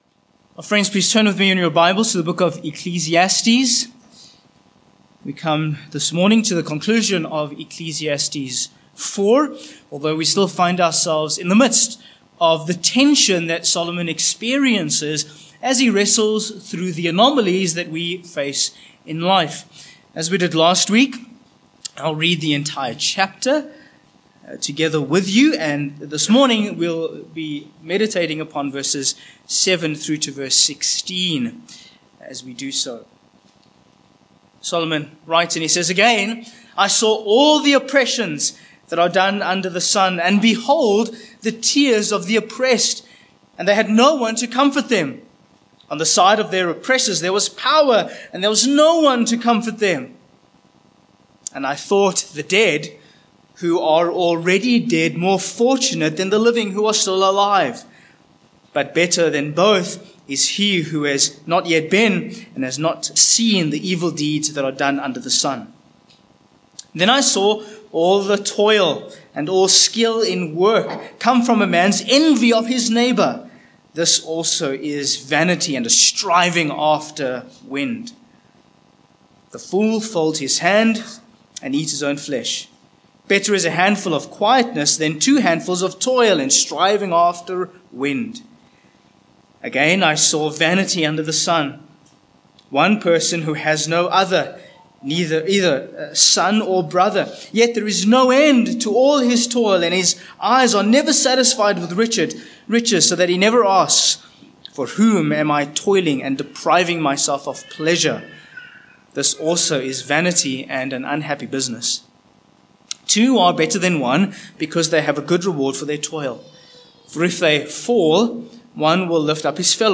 Ecclesiastes 4:7-16 Service Type: Morning Ecclesiastes 4:7-16 1.